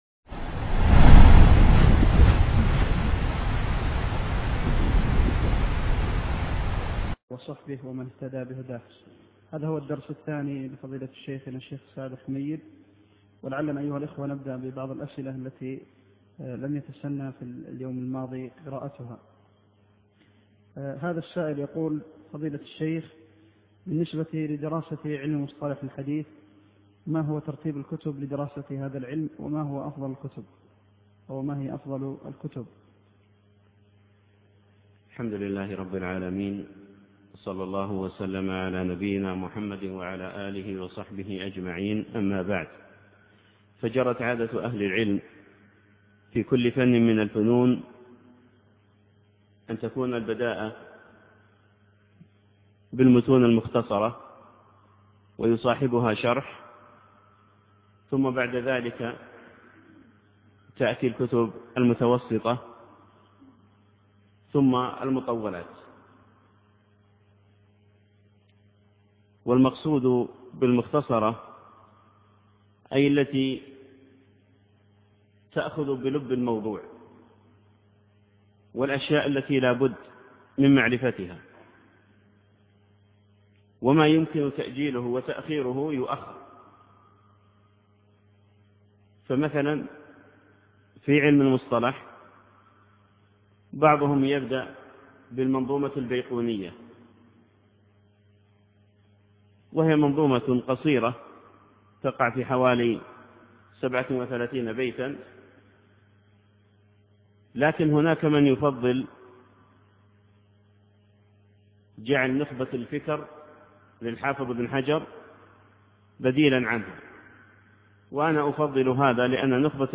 وقفات تربوية من سير علماء الحديث (الدرس الثاني